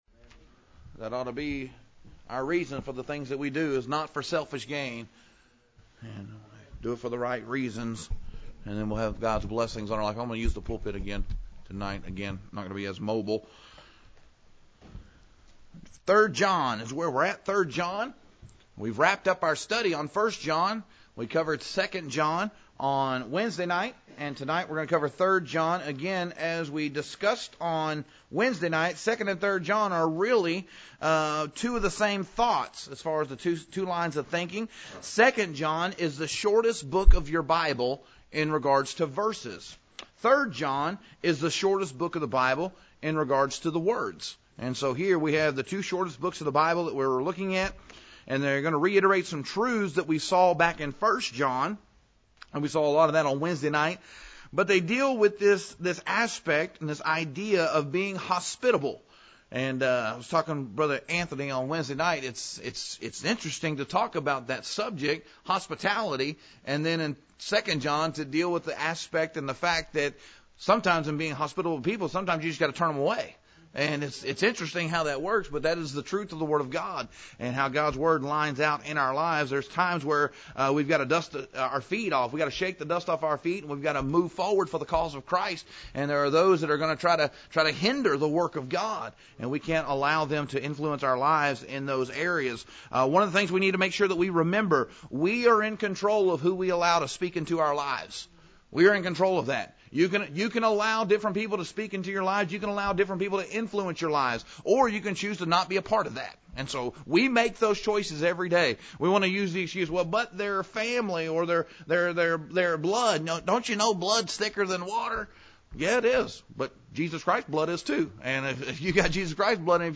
Introduction Sermon Title